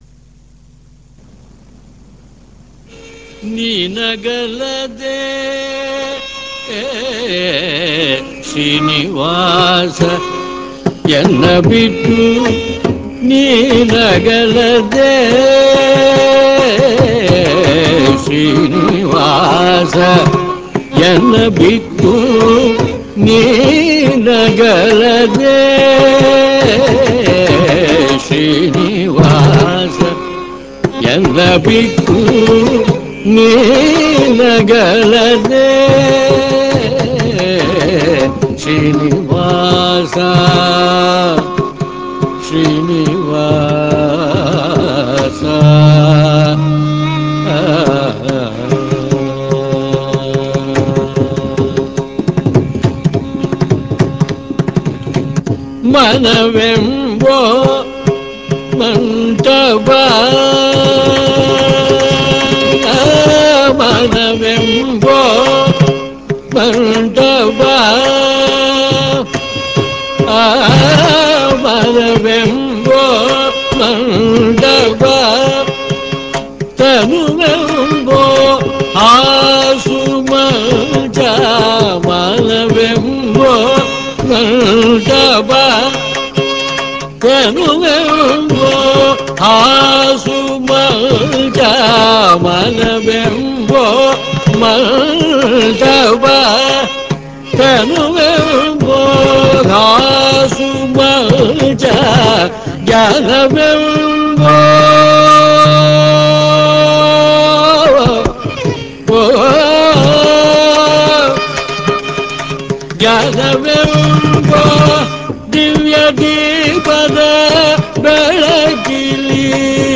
Sri Ranjini
Keerthanam